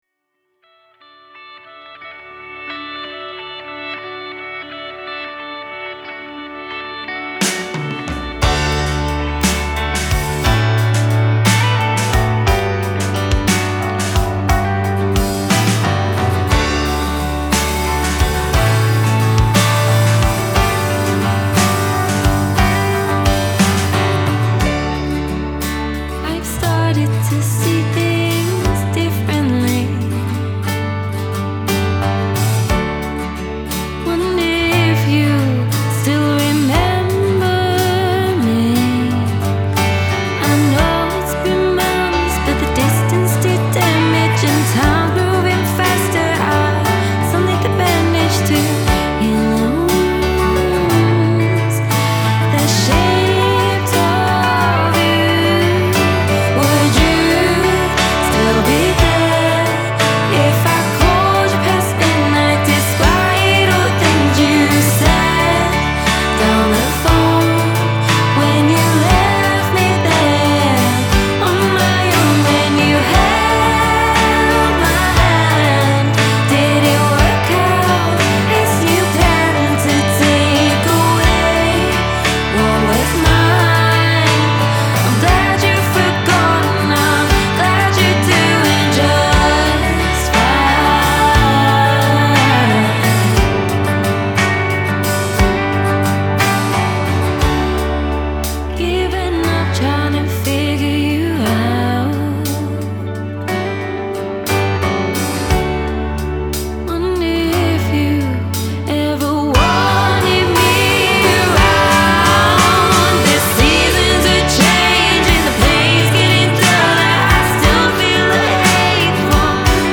Hull based singer-songwriter
recorded at Hull University
this impassioned, catchy slice of alt-pop